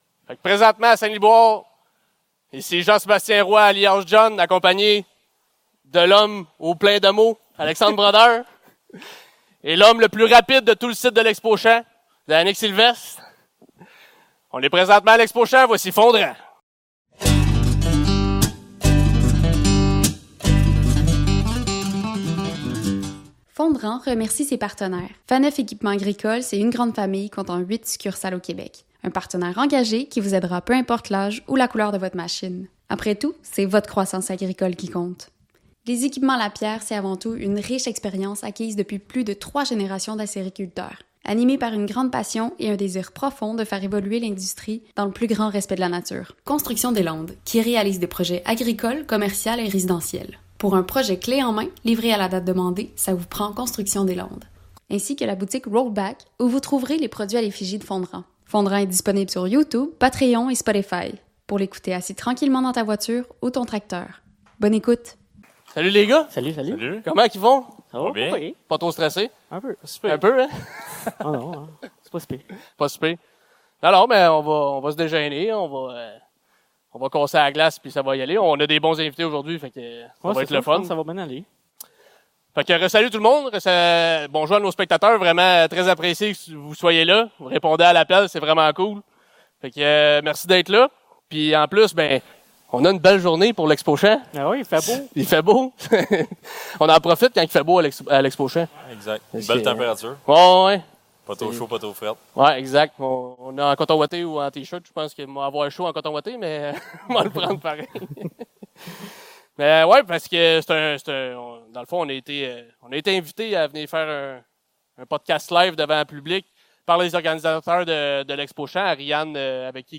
Spécialement pour la 25e édition de l'Expo-Champs, nous avons été invités à enregistrer un podcast devant public lors de la journée thématique de la relève agricole.